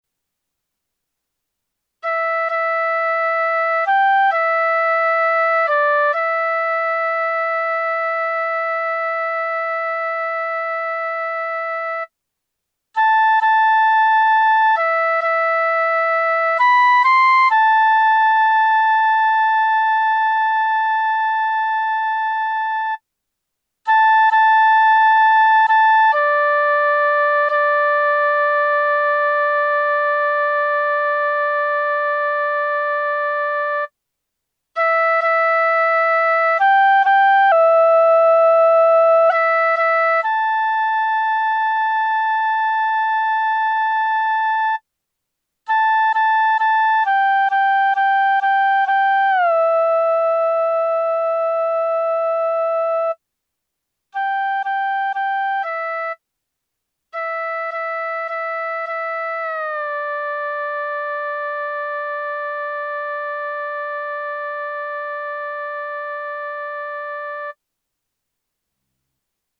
いにしへの、京言葉アクセントでの作曲です。